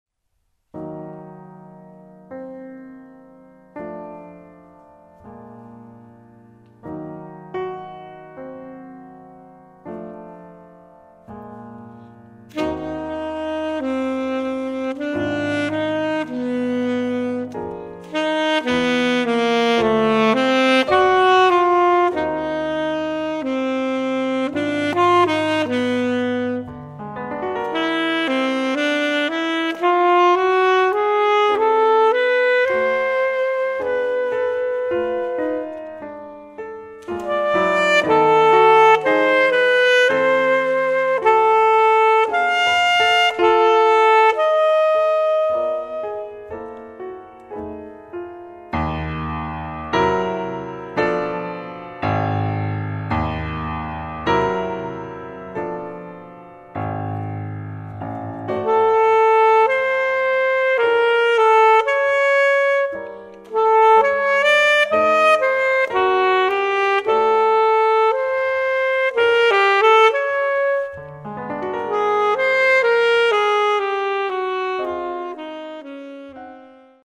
Altsaxophon
Klavier